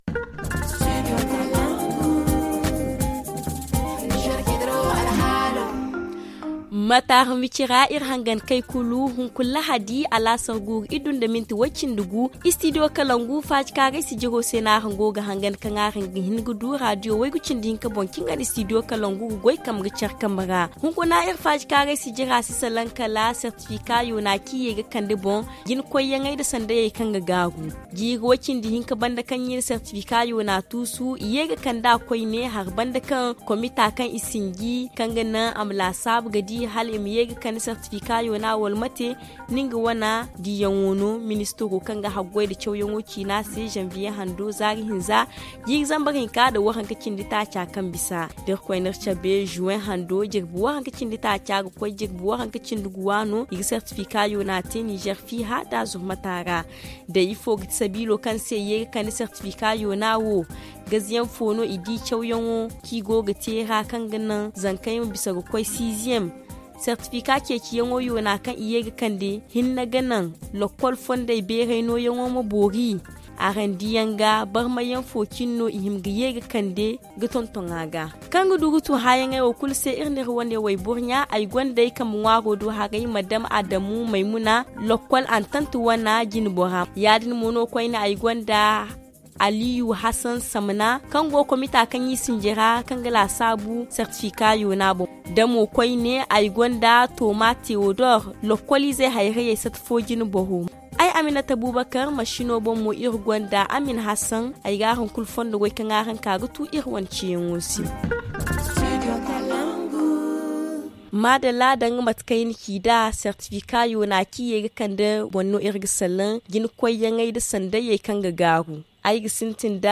ZA Le forum en zarma Télécharger le forum ici.